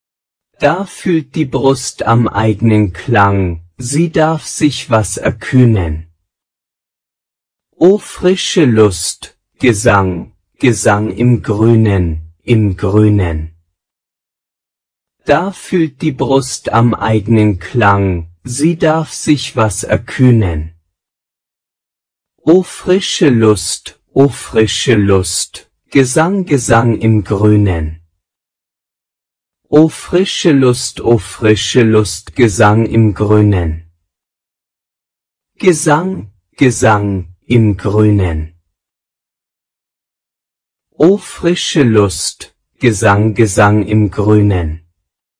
voix homme(52-81)
im-wald-prononce-garcon-52-81.mp3